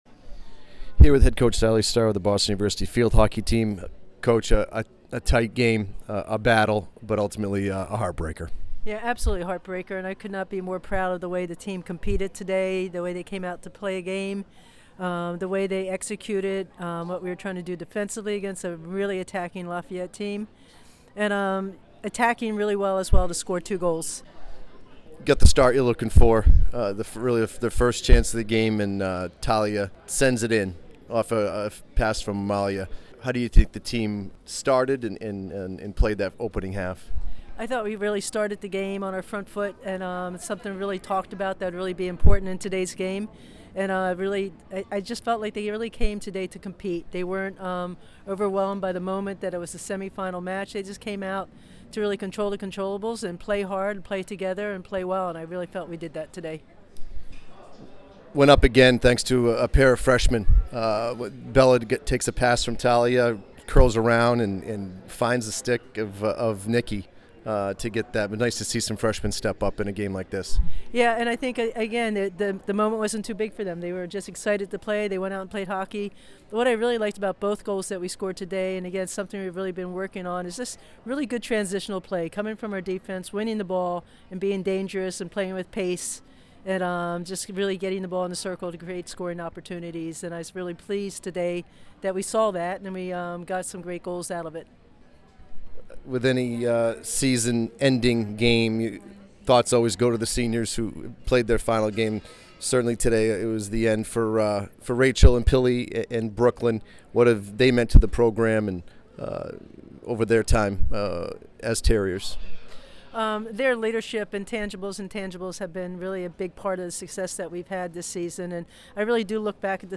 Field Hockey / PL Semifinal Postgame Interview